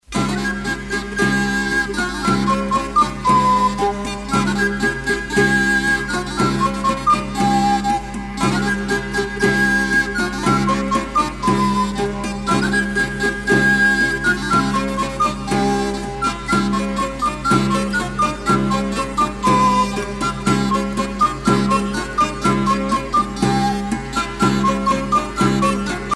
danse : moresque